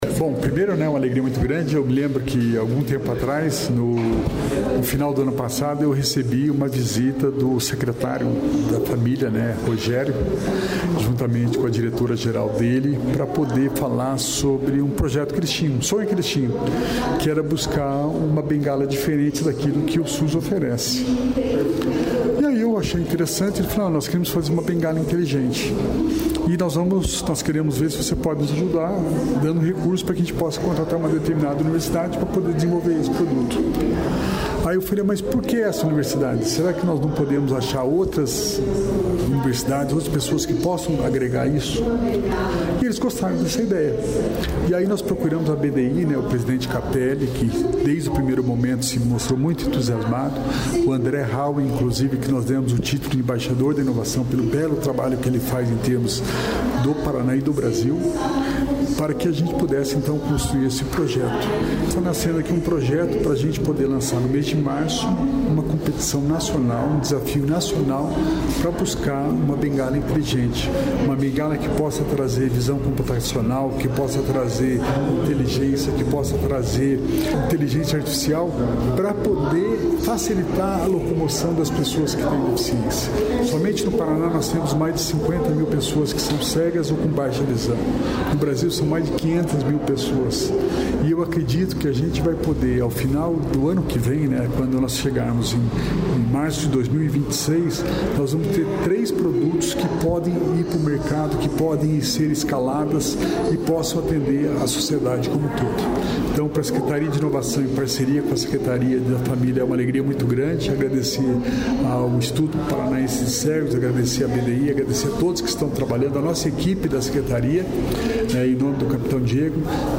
Sonora do secretário da Inovação, Alex Canziani, sobre as bengalas inteligentes